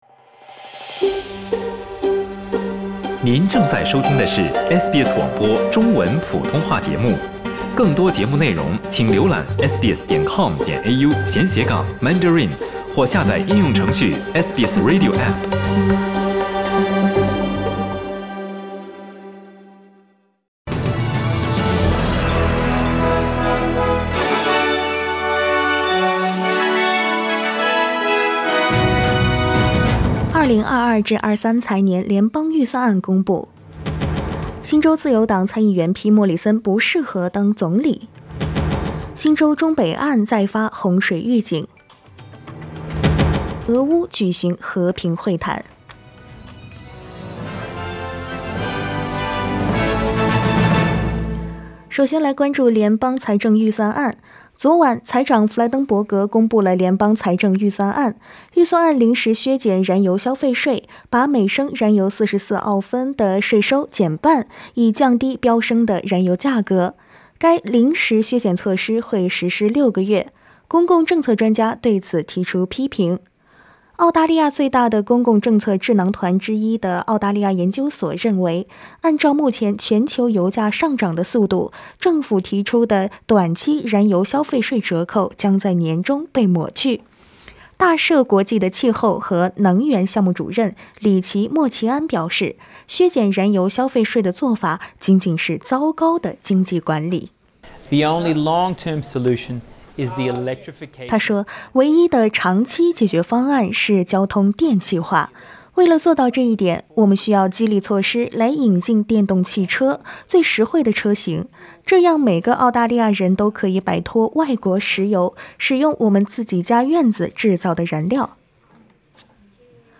SBS早新闻（3月30日）
SBS Mandarin morning news Source: Getty Images